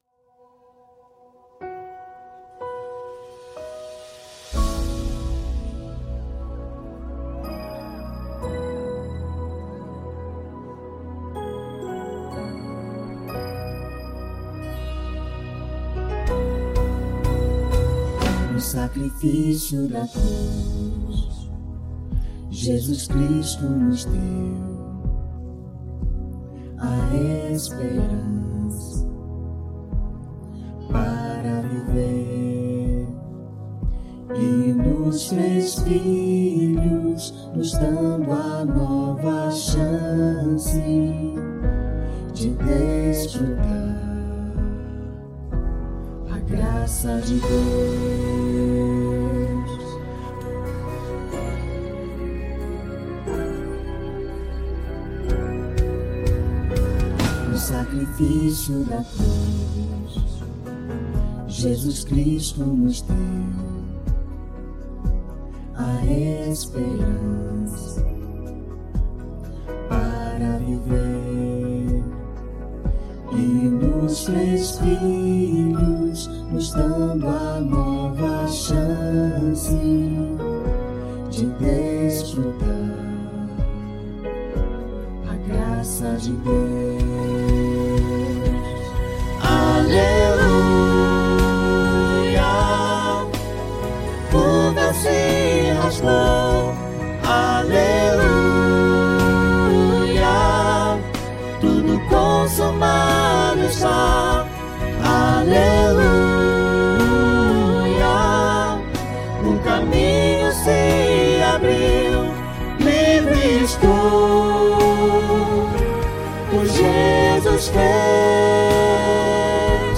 Arranjo Coral (SCTB)